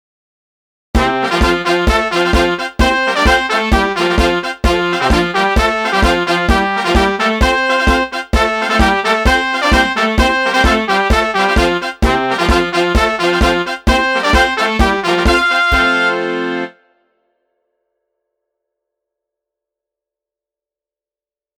最初はゆっくりとしたノーマルものから、大部分の人ができるようになったときのレベルを上げてテンポを上げた音源も掲載。
130 Kintaro_temp130
Kintaro_temp130.mp3